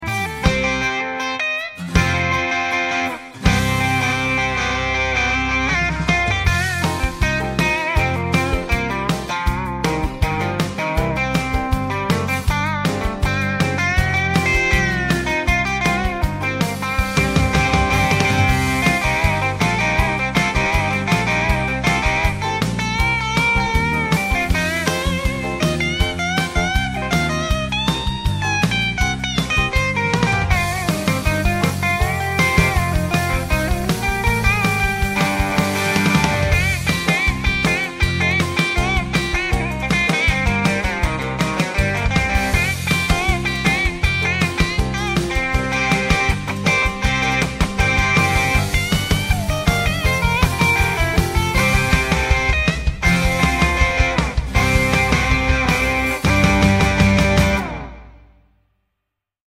quick Session with our Virtual Guitar